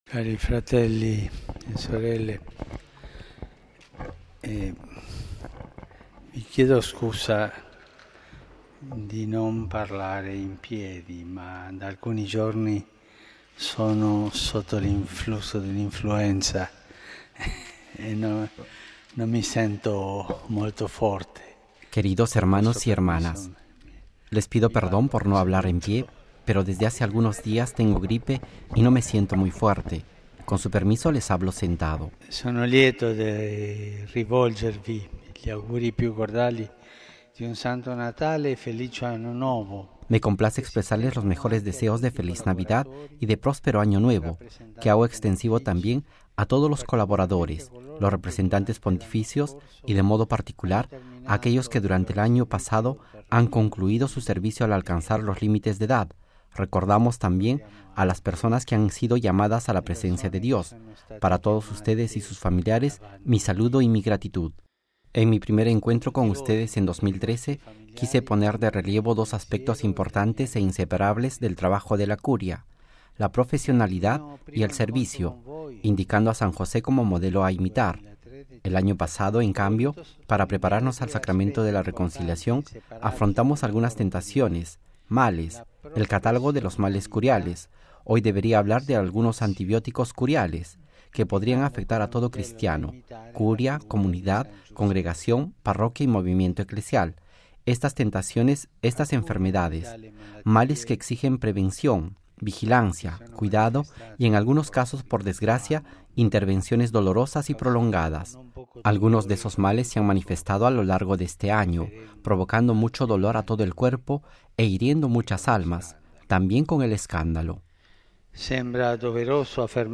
(RV).- Tal como estaba previsto, el tercer lunes de diciembre el Papa Francisco celebró un encuentro con los miembros de la Curia Romana para el tradicional intercambio de felicitaciones ante la inminente Navidad.